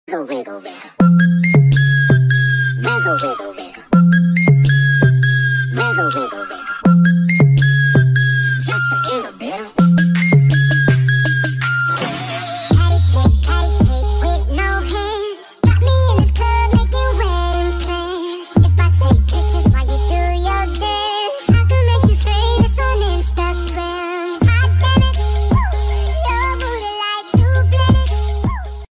Kategorien Lustige